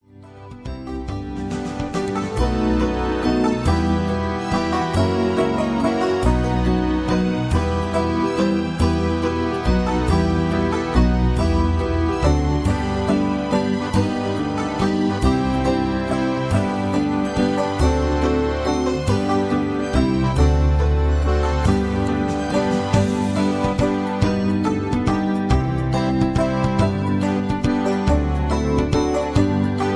Tags: backing tracks , irish songs , karaoke , sound tracks